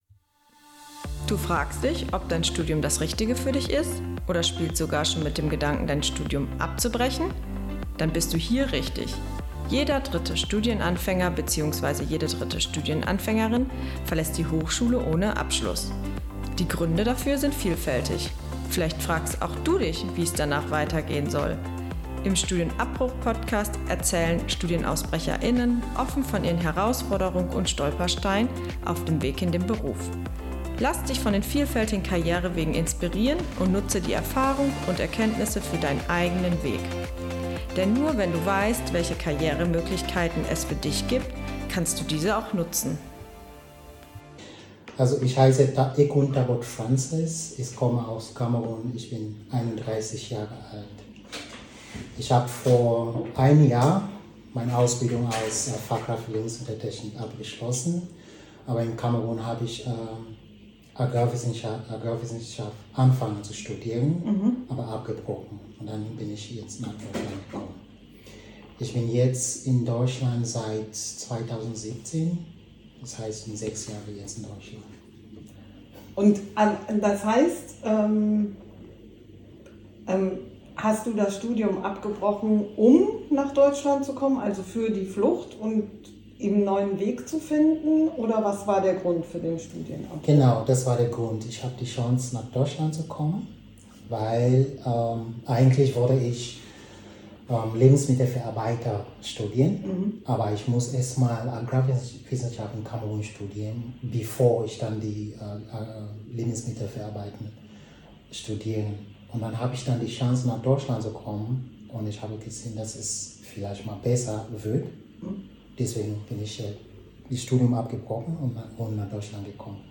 Wir entschuldigen die Tonqualität!